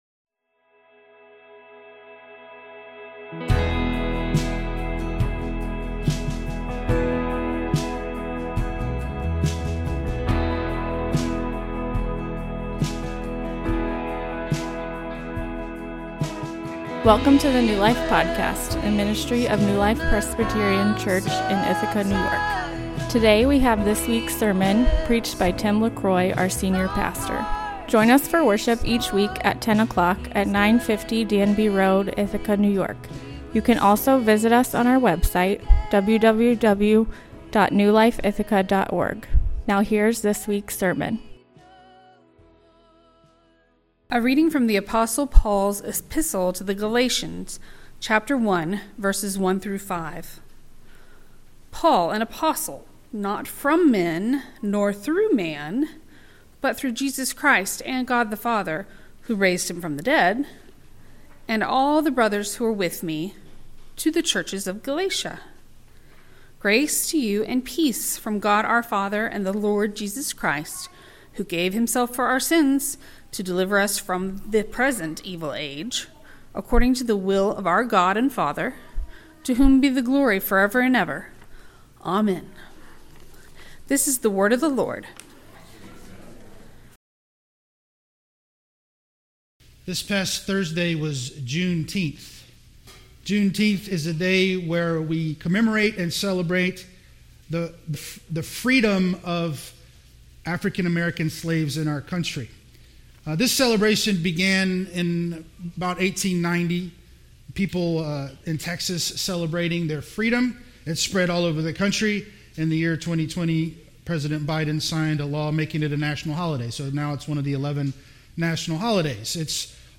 An introductory sermon to Paul’s letter to the Galatians.